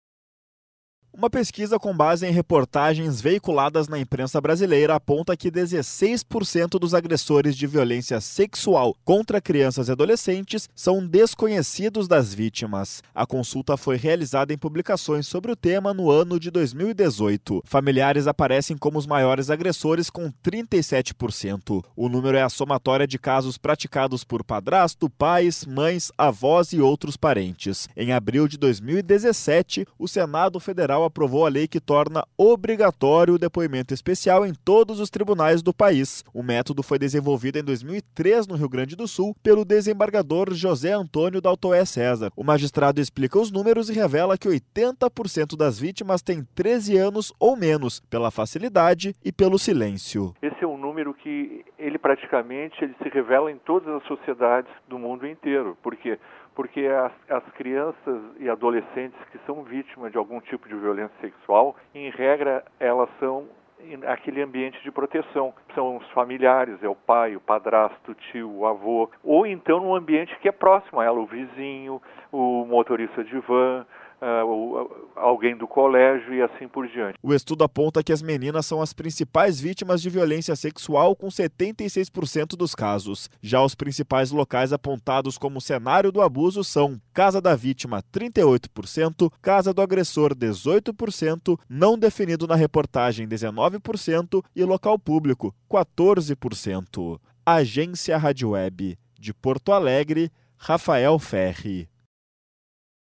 Violência sexual: 16% dos agressores são desconhecidos Clique no “Play” e Ouça a Reportagem